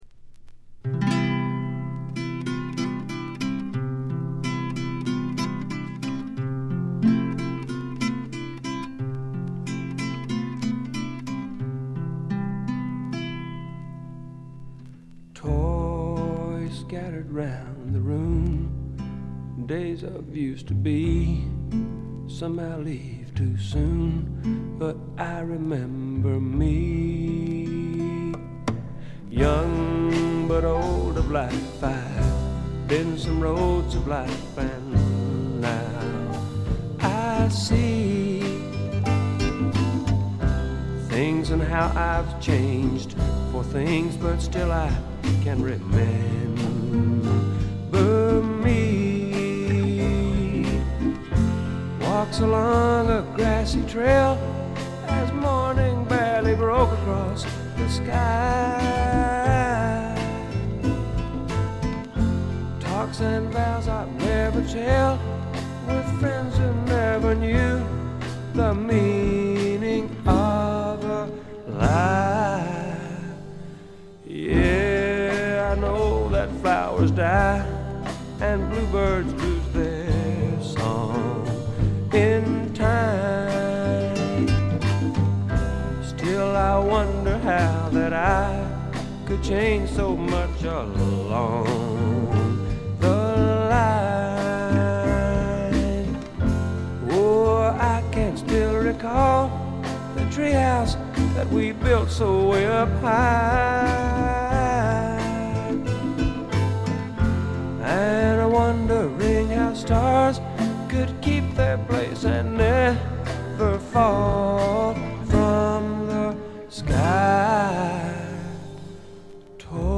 部分試聴ですが、ごくわずかなノイズ感のみ。
あまりナッシュビルぽくないというかカントリーぽさがないのが特徴でしょうか。
試聴曲は現品からの取り込み音源です。
Guitar, Vocals, Piano, Vibes